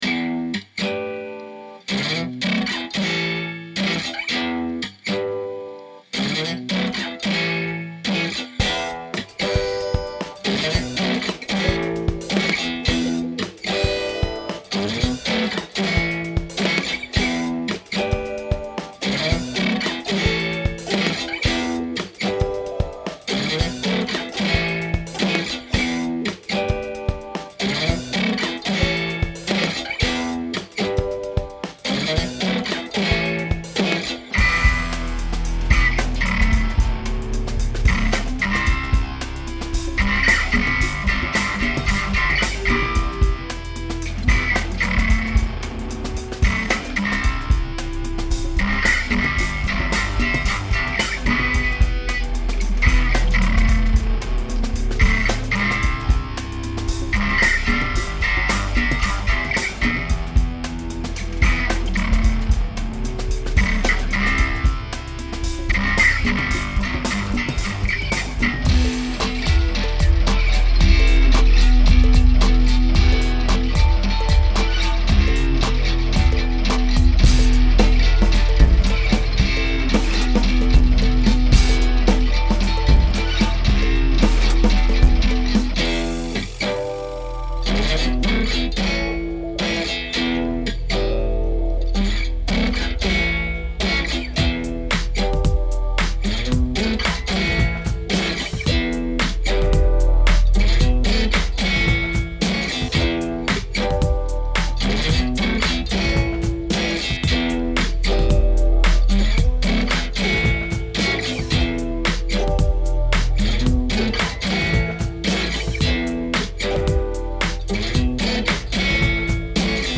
Here’s a very rough track. I’m recording using the laptop’s built-in mic and an acoustic guitar, then processing it with Garage Band effects.
I added some drums to fill it out a bit, which points out my wandering rhythm.